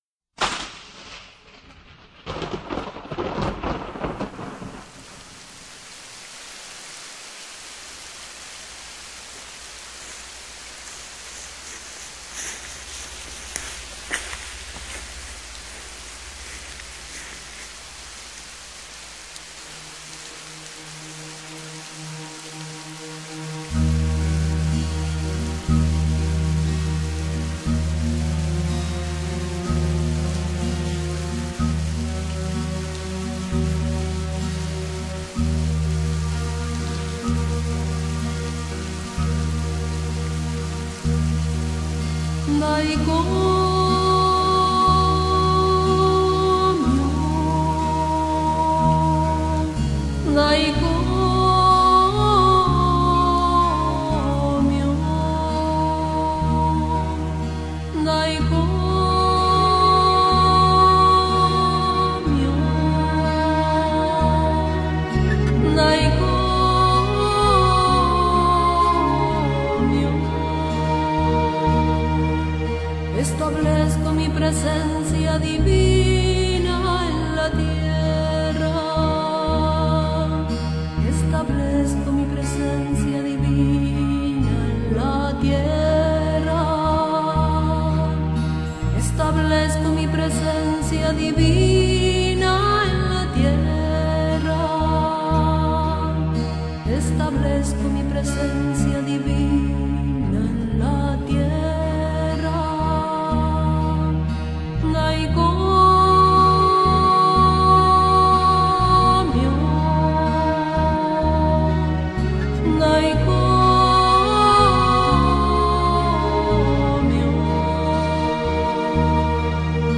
CHANTS REIKI